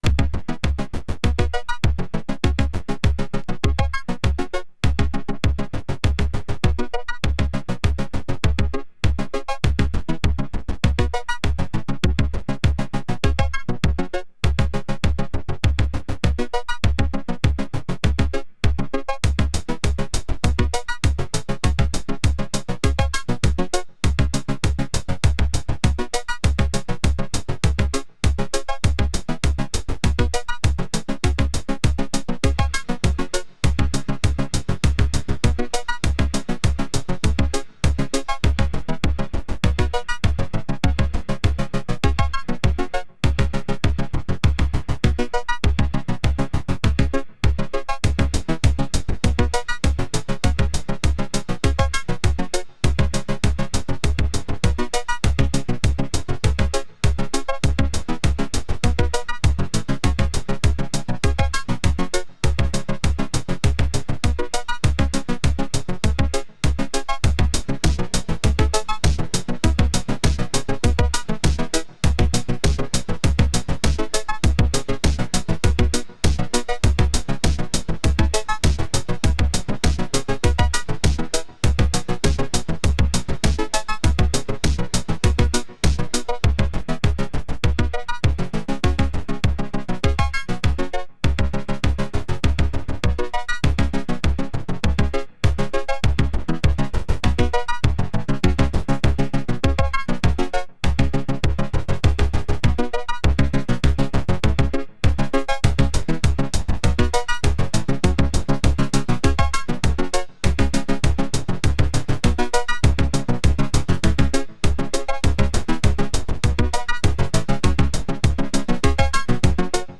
Experiments, Sketches, Drafts
I was playing around with driving the synth via Ableton and recorded this little demo, still unfinished.